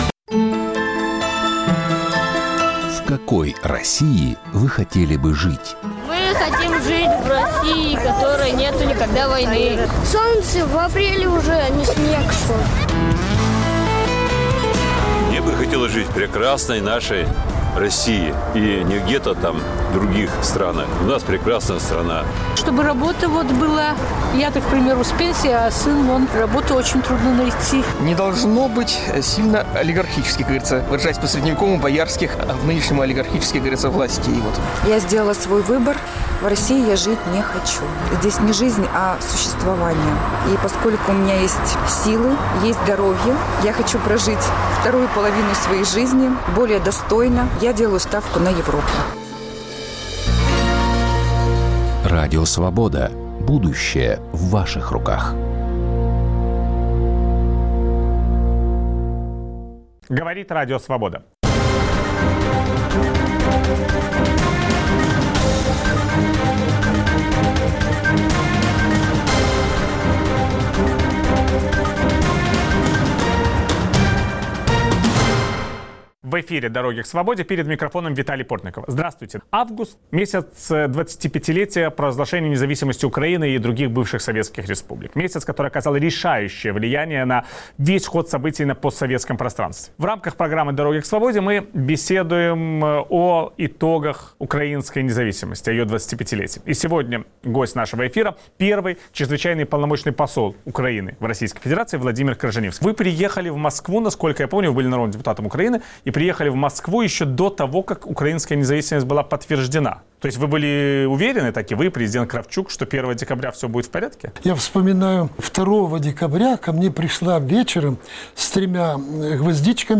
Виталий Портников беседует с первым послом Украины в Российской Федерации Владимиром Крыжанивским